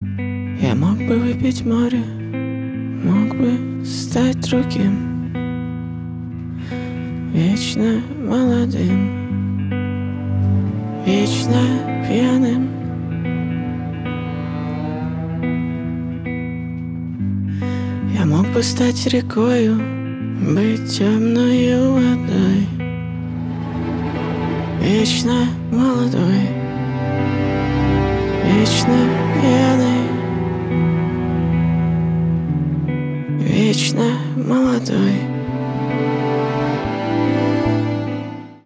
Поп Музыка
грустные # кавер # спокойные